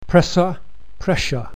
presserpressure.mp3